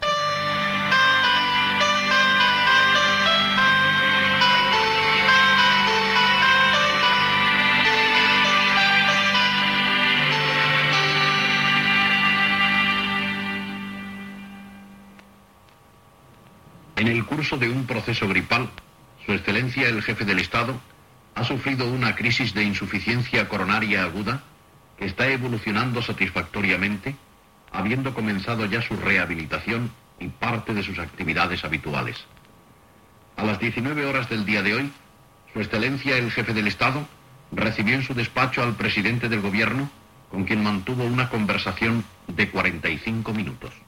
Lectura del comunicat mèdic del "Generalísmo" Francisco Franco. Pateix una insuficiència coronària aguda dins d'un procés gripal
Informatiu